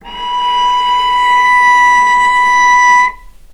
vc-B5-mf.AIF